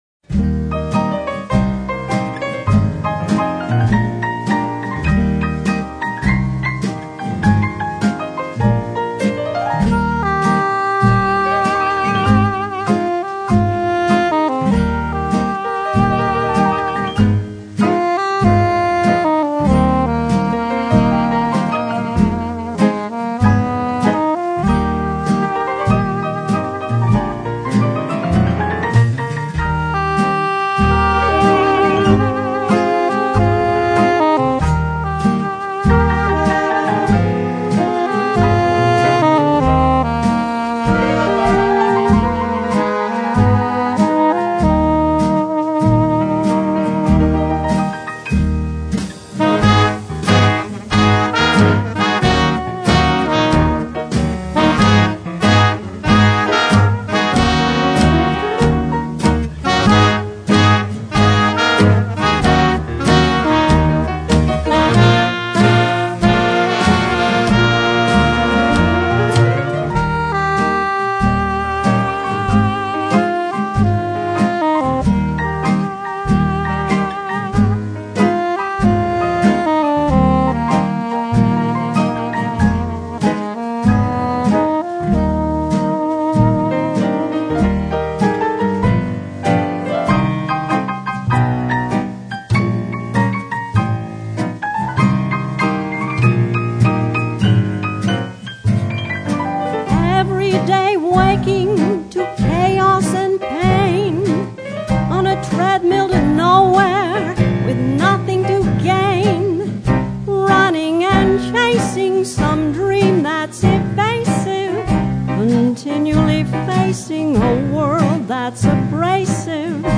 1920s Syncopated Jazz and 1930s-Big Band Swing styles